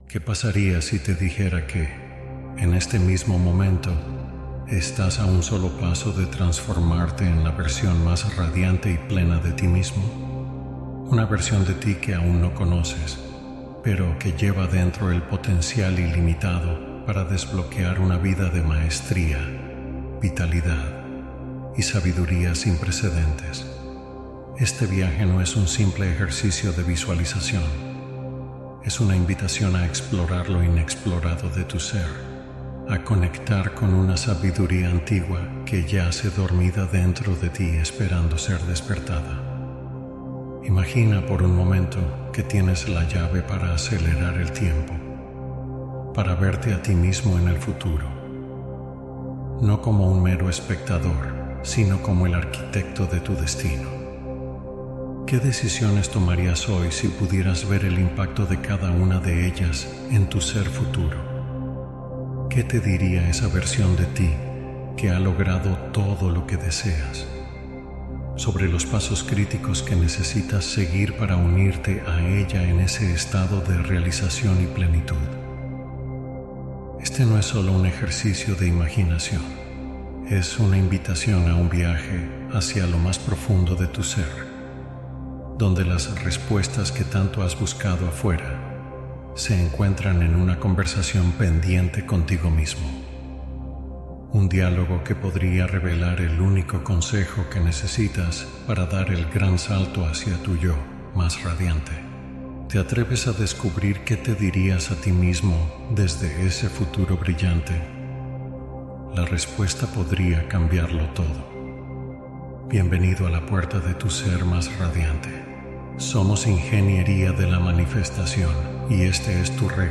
Conecta con tu versión más elevada mediante esta meditación guiada